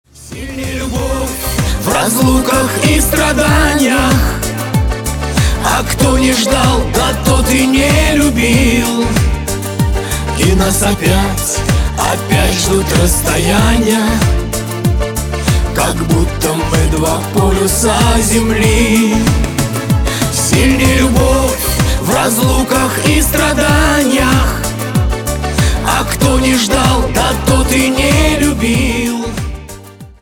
• Качество: 320, Stereo
душевные
дуэт
русский шансон